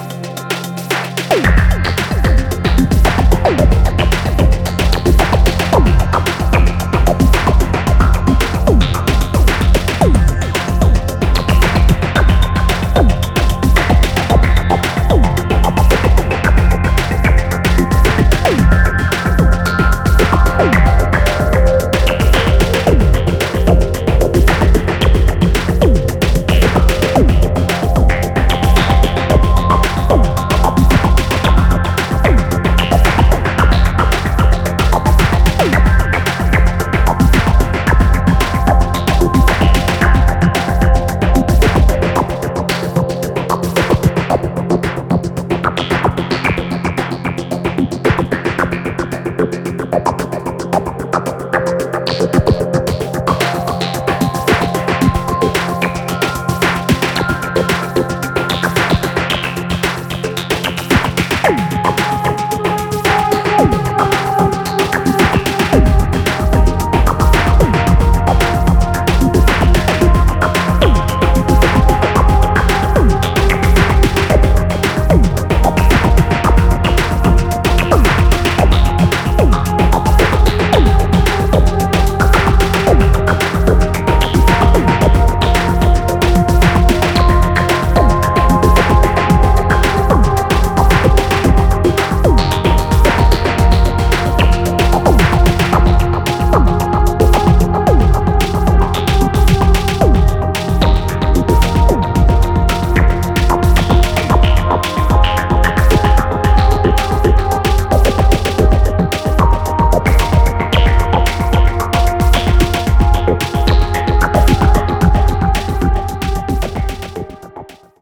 Electro Electronix Techno Acid Trance Dub Techno